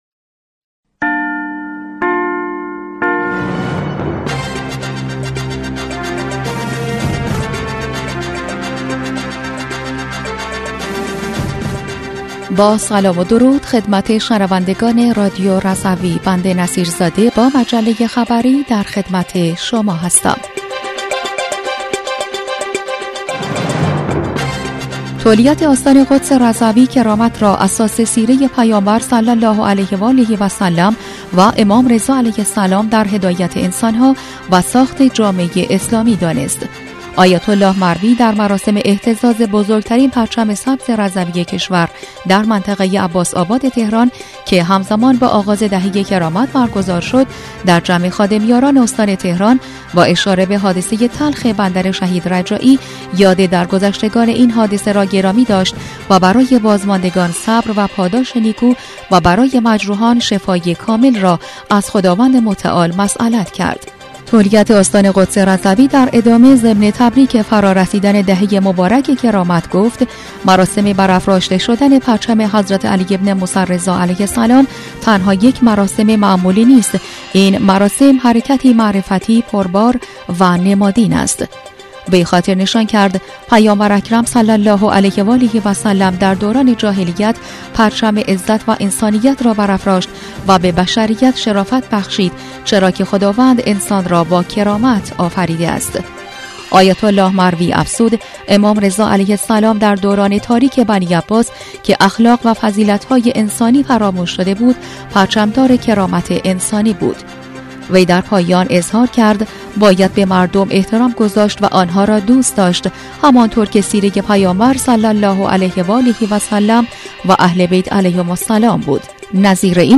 در بسته خبری پنجشنبه ۱۱ اردیبهشت رادیو رضوی؛ خبر های مختلفی از قبیل اهتزاز بزرگ‌ترین پرچم سبز رضوی کشور تا توزیع غذای متبرک حضرتی در بین امداد گران حادثه بندر شهید رجایی گنجانده شده است.